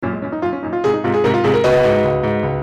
GAIN 300％で歪ませたもの（比較しやすいように音量を下げてます）